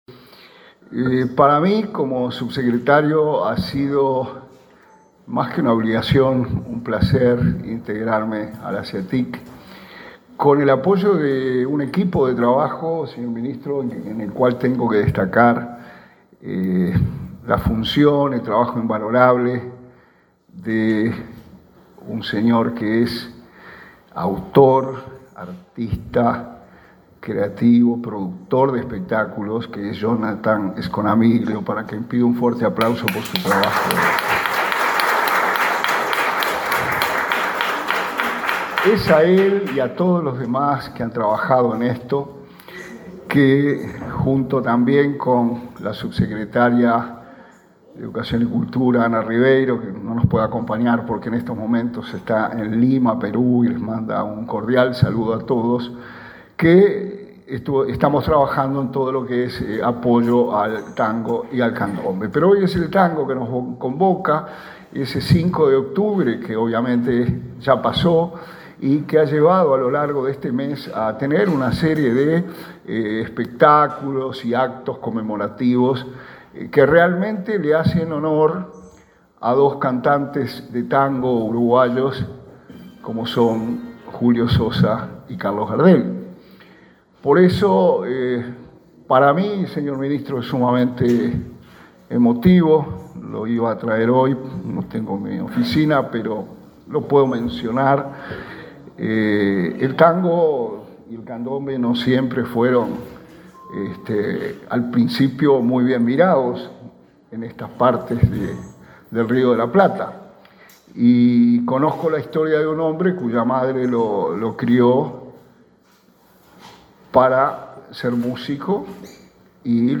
Palabra de autoridades del Ministerio de Turismo
Este miércoles 25 en Montevideo, el subsecretario de Turismo, Remo Monzeglio, y el ministro, Tabaré Viera, participaron de un acto por el Día Uruguayo